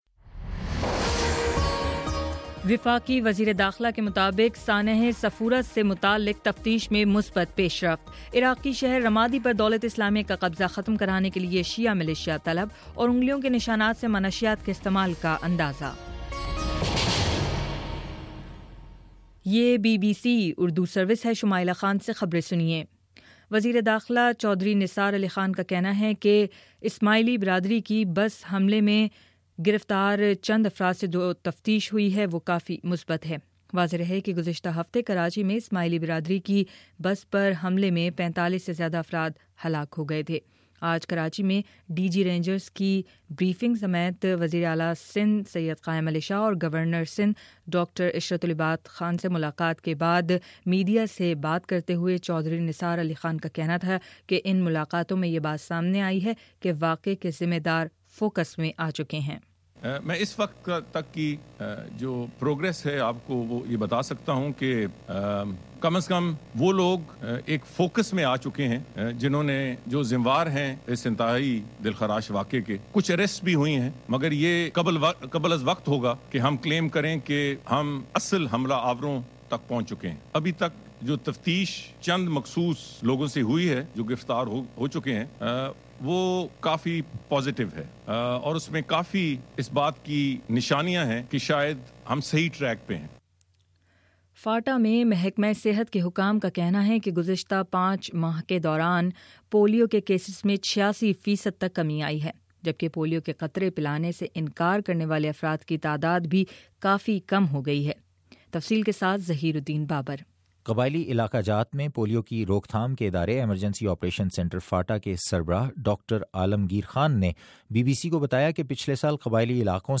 مئی 18: شام چھ بجے کا نیوز بُلیٹن